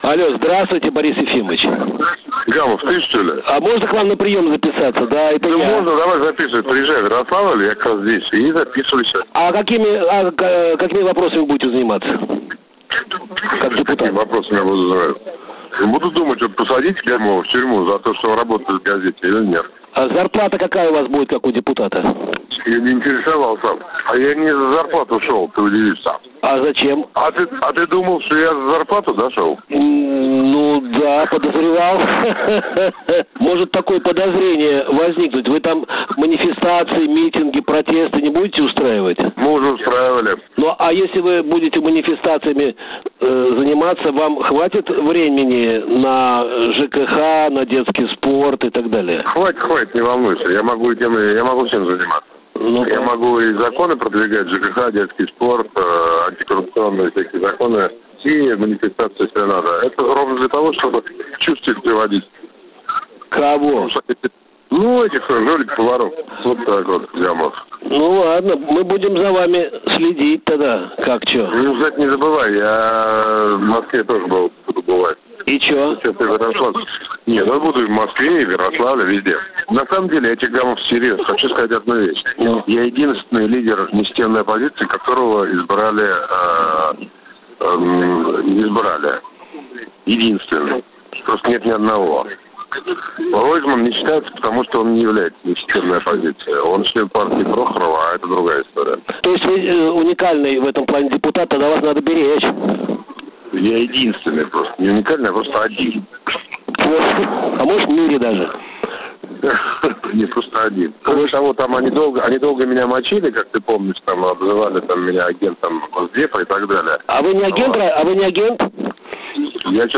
История. Интервью с Борисом Немцовым
Мы позвонили оппозиционному политику…